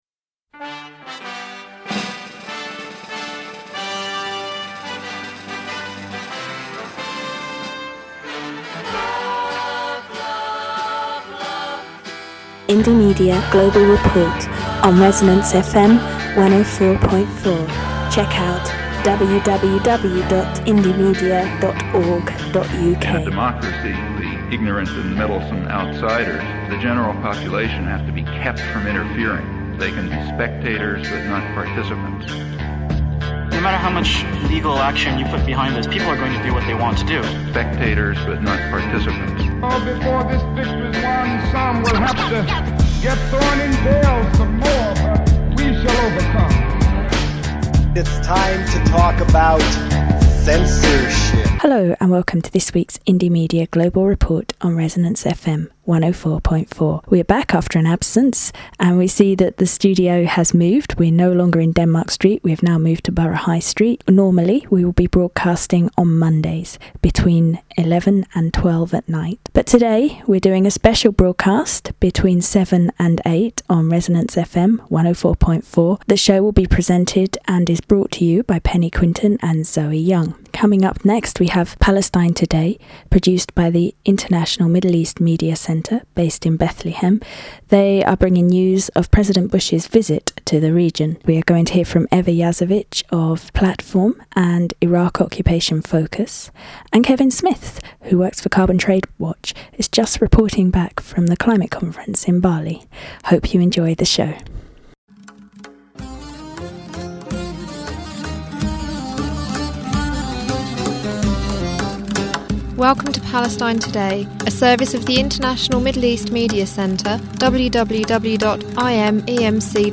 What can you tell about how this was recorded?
Indy Global Reports is back on ResonanceFM104.4. Indy Global Reports returned to London's air waves on Thursday10th of Jan 7pm for a special broadcast before it's regular slot of 11.00 till 12.00pm every Monday .